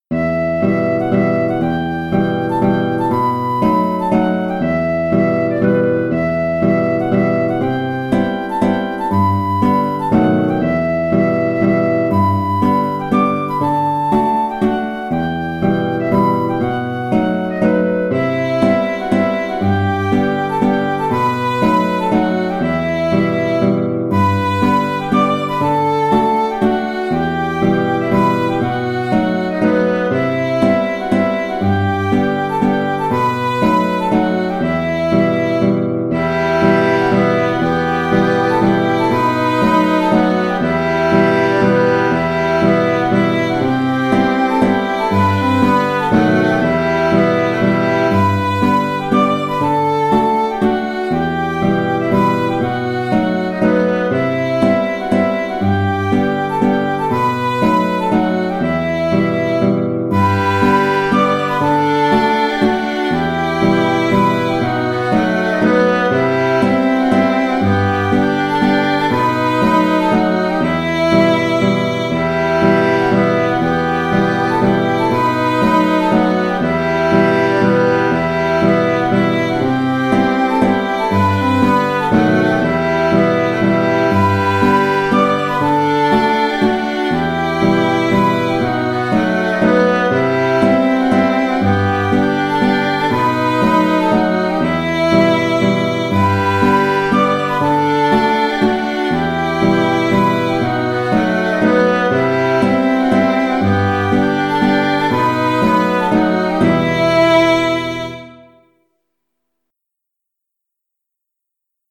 Vent d'Automne (en Em) (Mazurka) - Musique folk
Plus difficile à jouer pour les diatonistes à cause du ré# et de l'accord de B7.